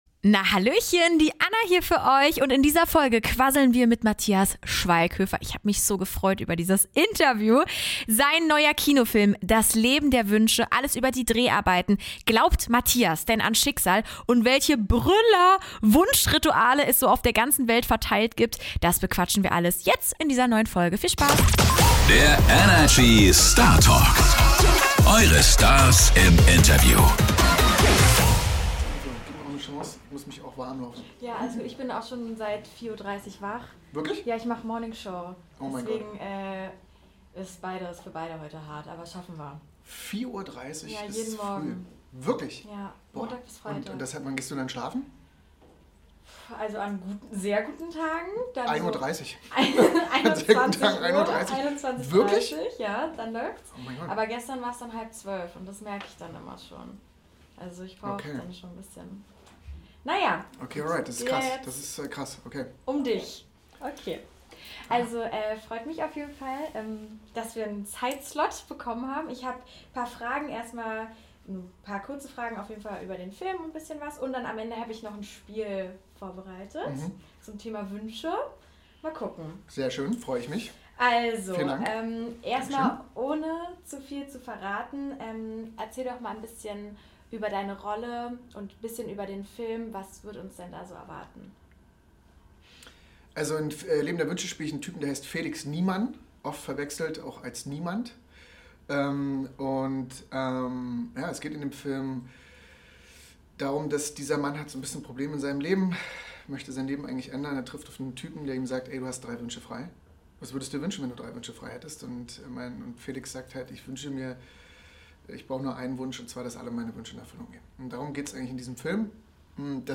Ein Gespräch voller ehrlicher Einblicke und Momenten, bei denen man gleichzeitig lachen und staunen muss.